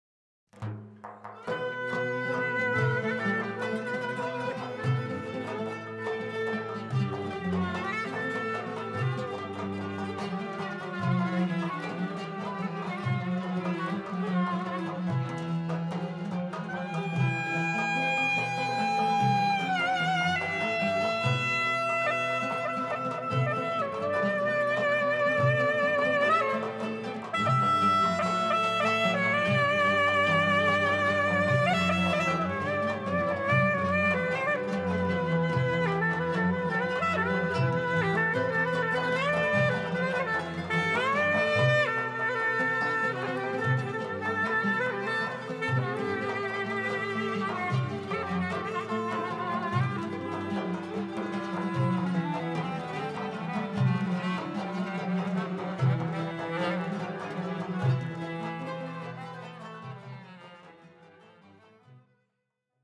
Genres: Turkish Traditional, Roman.
clarinet
violin
kanun
darbuka
Recorded on November 3rd, 1999 in Istanbul at Audeon Studios